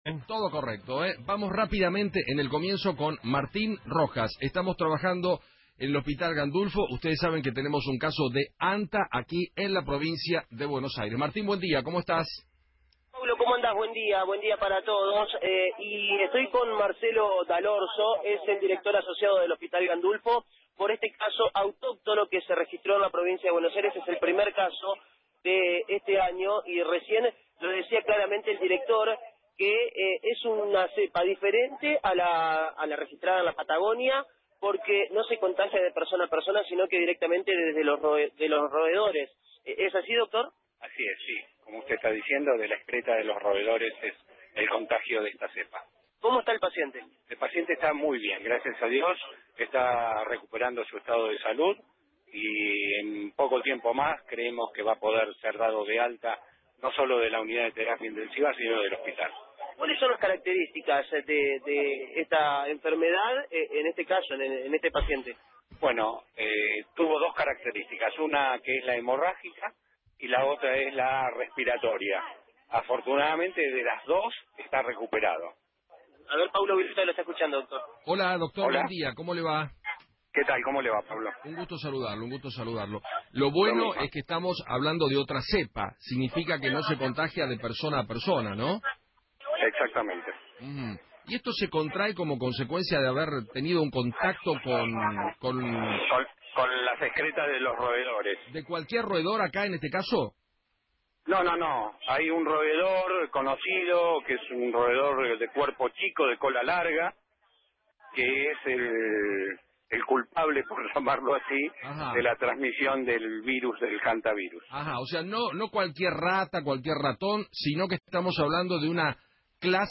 habló en Feinmann 910 y contó que el Hantavirus “Sólo se contagia de roedores